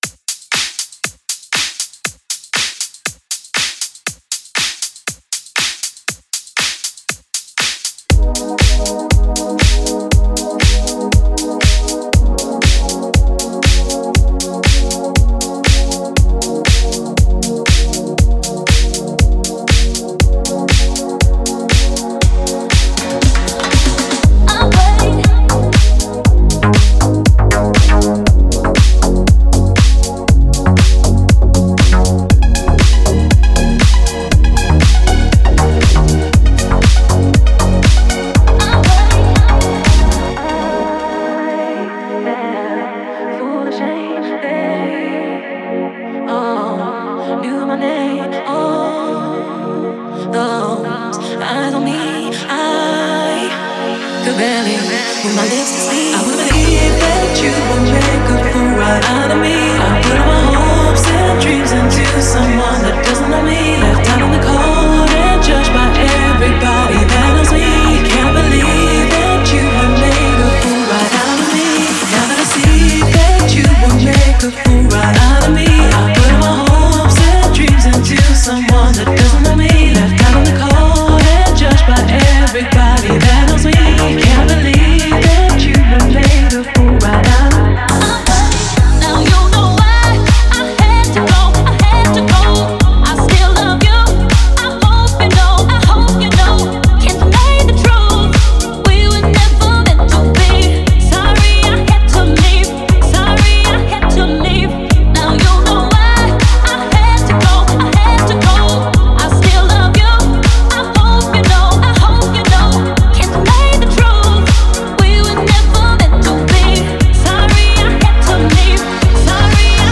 это яркая и мелодичная трек в жанре дип-хаус